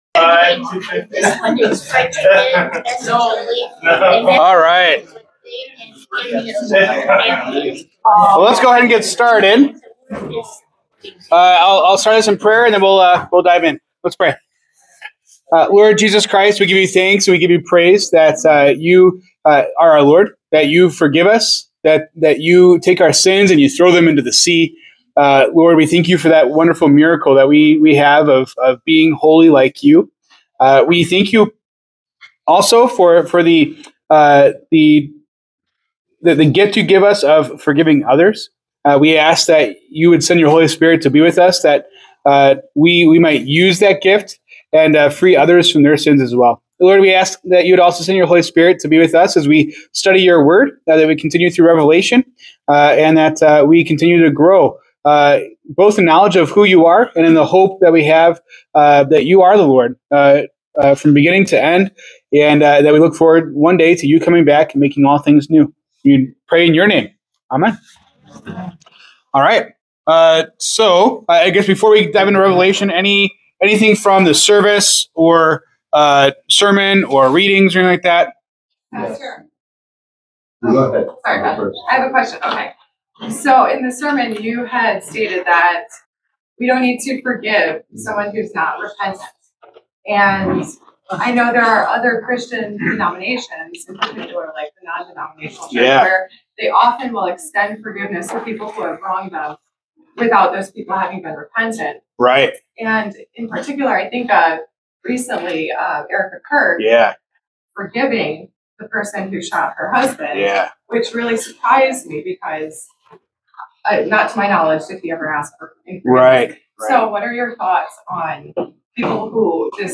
October 5, 2025 Bible Study
Discussion on forgiveness and Revelation 1:4-20.